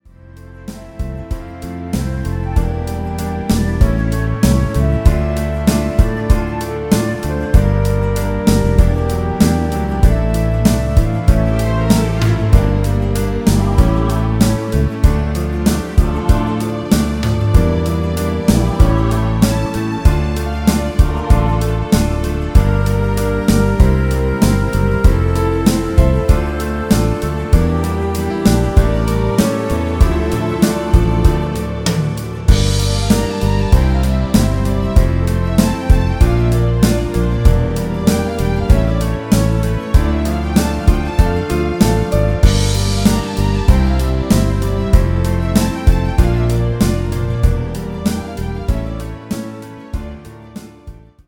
live opéra Garnier 2008